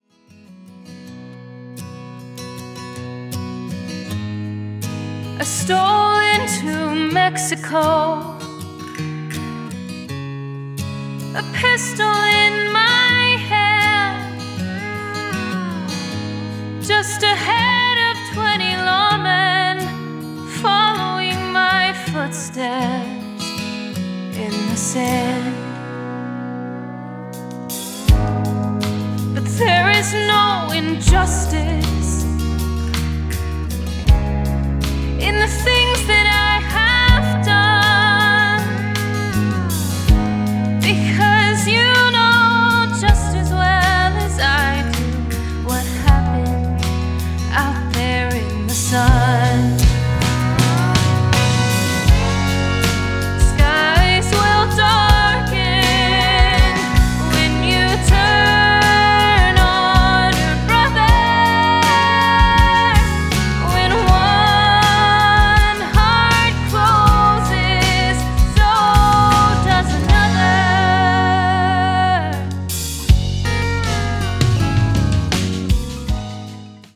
a wonderful young singer
emotional ballads